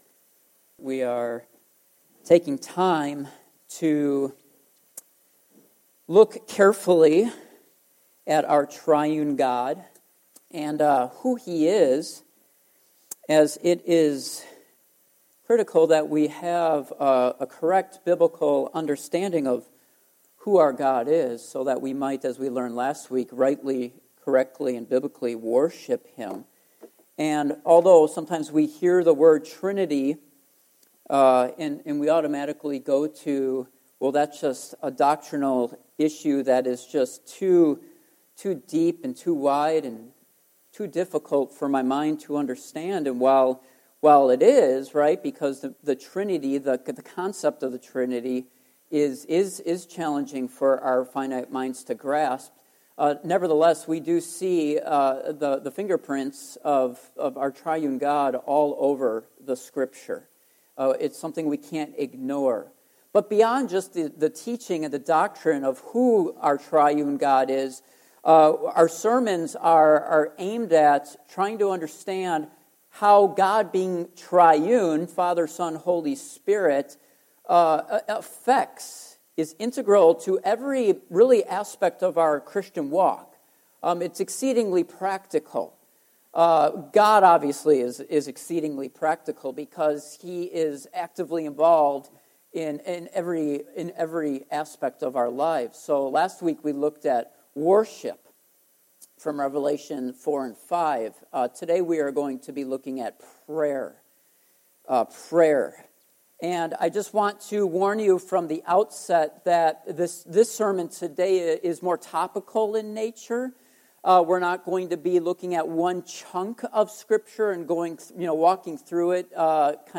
Various Service Type: Morning Worship Topics